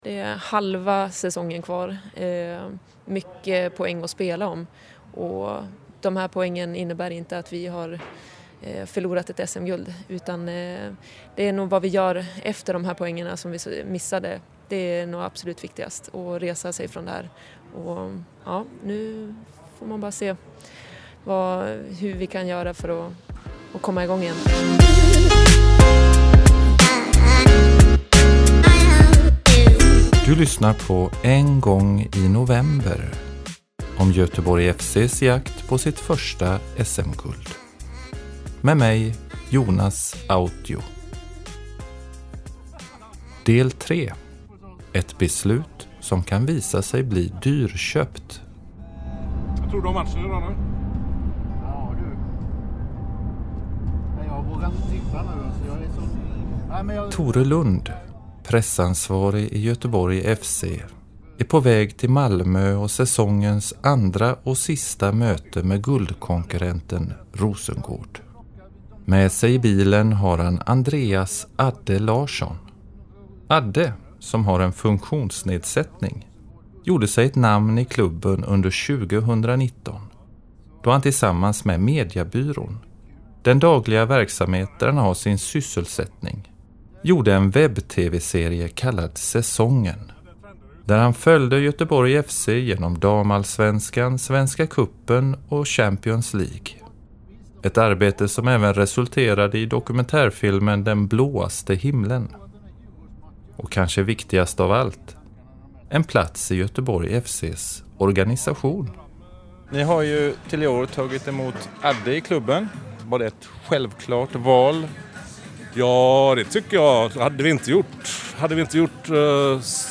Intervjuer